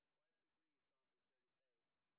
sp05_street_snr10.wav